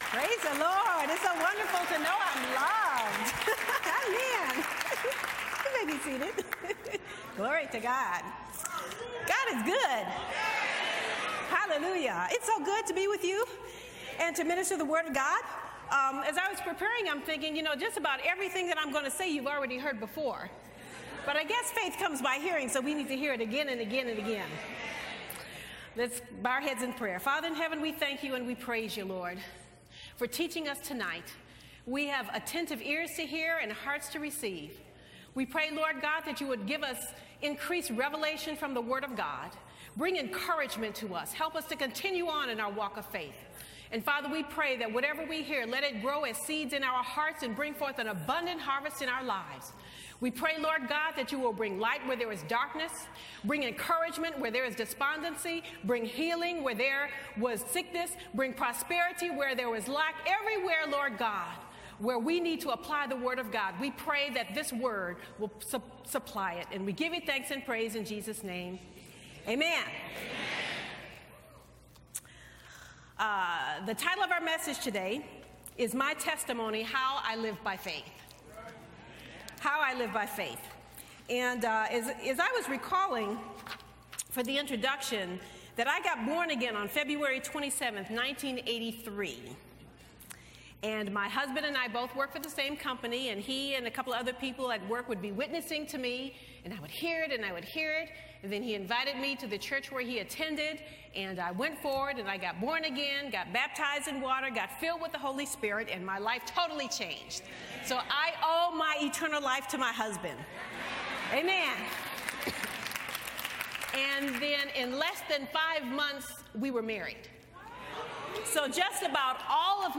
heartfelt teaching